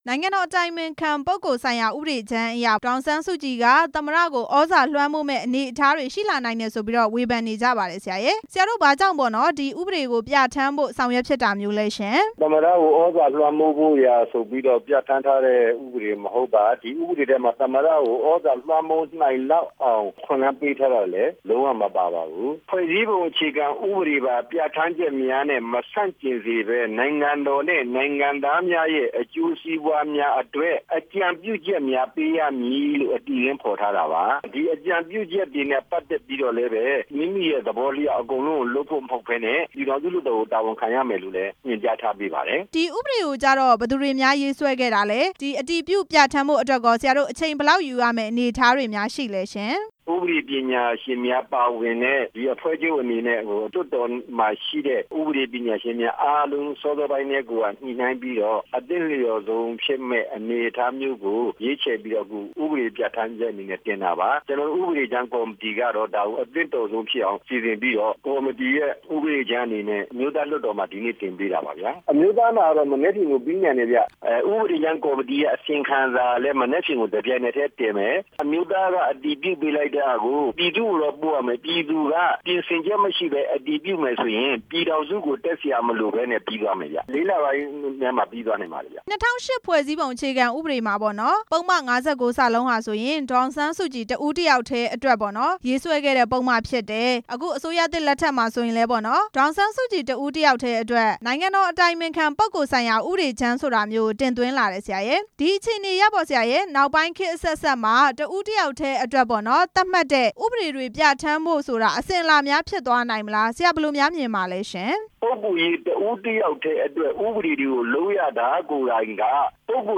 နိုင်ငံတော်အတိုင်ပင်ခံ ပုဂ္ဂိုလ်ဆိုင်ရာဥပဒေ ဦးအောင်ကြည်ညွှန့်နဲ့ မေးမြန်းချက်